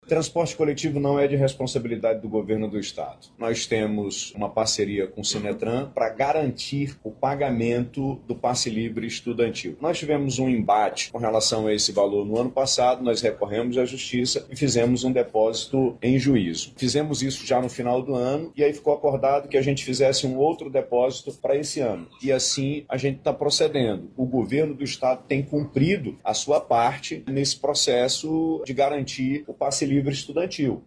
Em resposta, o governador Wilson Lima disse que segue uma determinação da Justiça, que prevê repasses da administração estadual ao Sinetram.